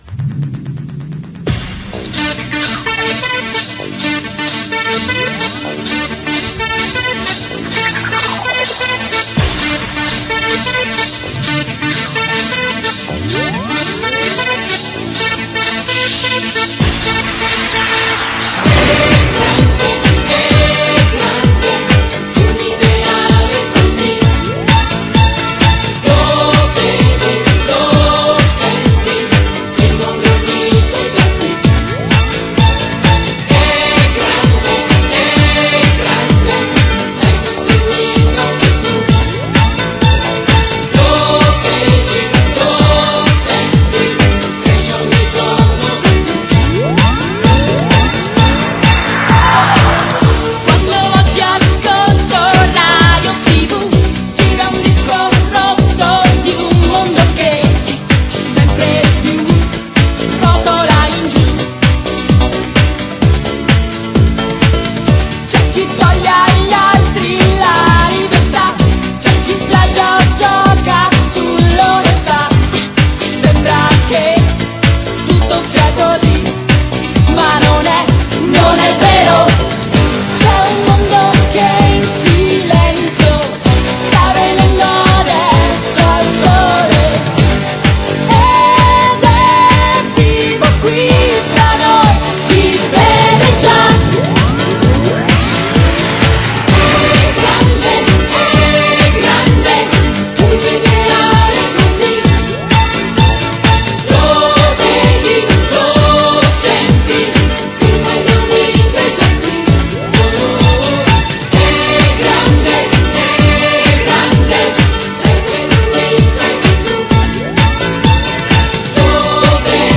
version mono basse qualité récupérée sur youtube